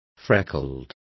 Complete with pronunciation of the translation of freckled.